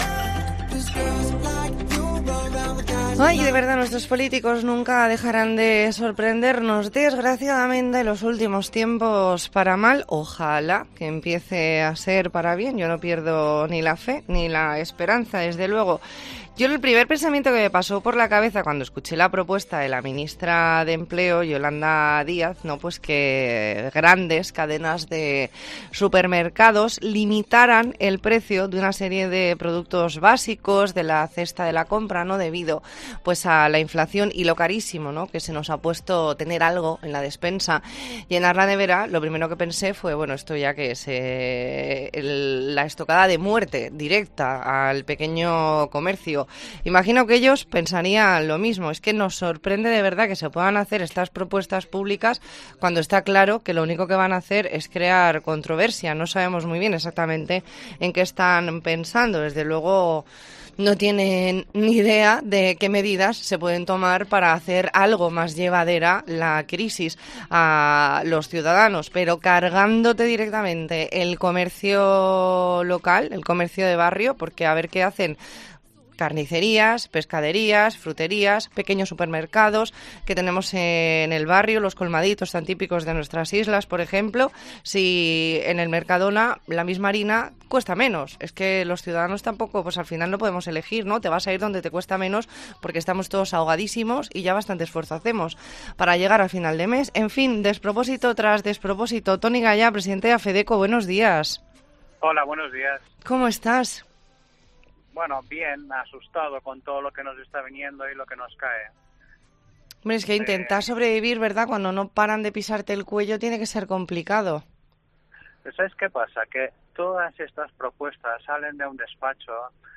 E ntrevista en La Mañana en COPE Más Mallorca, lunes 12 de septiembre de 2022.